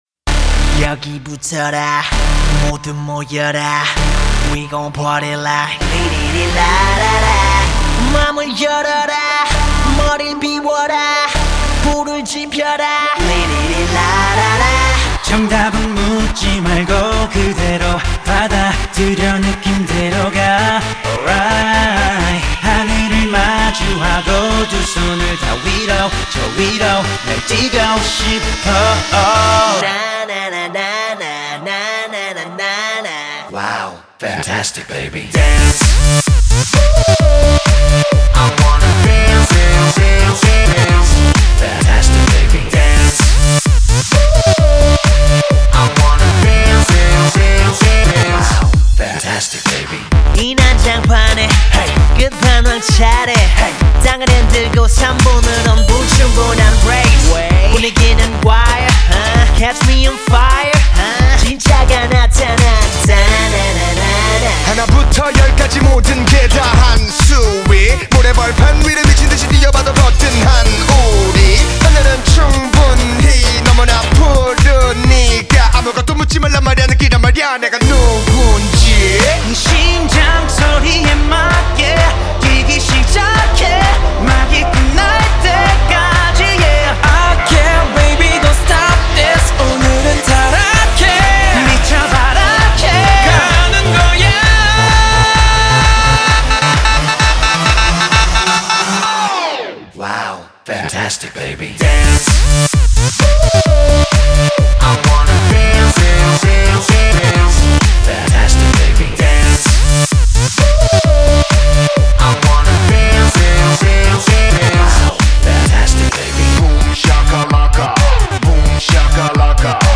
хочу вам дать послушать корейскую попсу